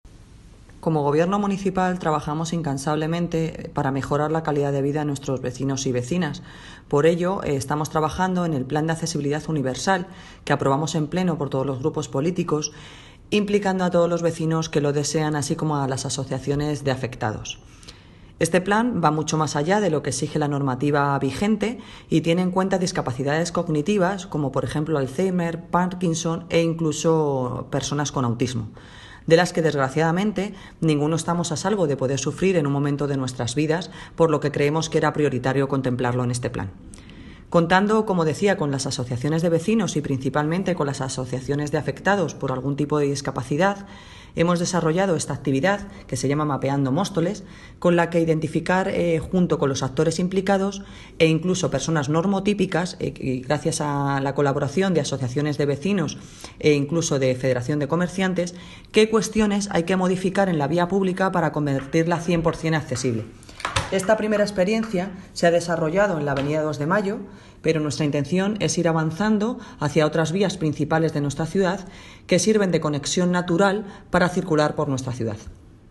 Audio - Noelia Posse (Concejala de obras, infraestructuras, mantenimiento de vías públicas y festejos) Accesibilidad